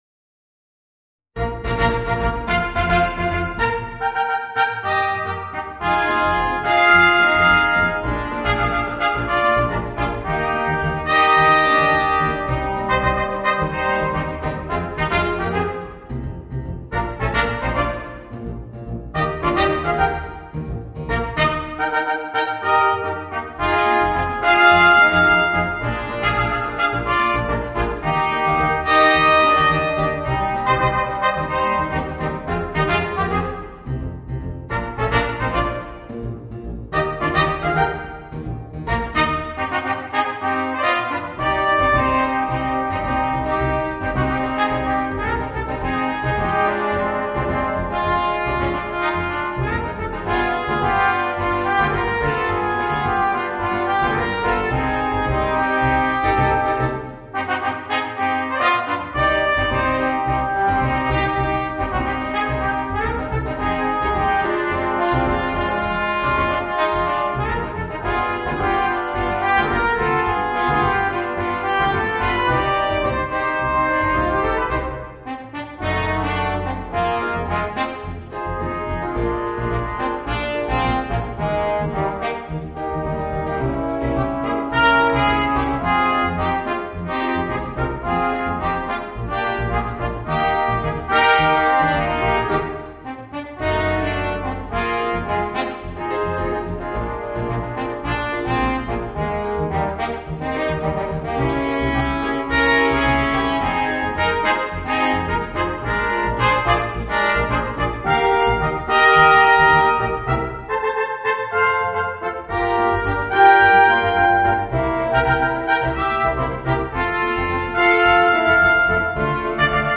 Voicing: 4 - Part Ensemble and Rhythm Section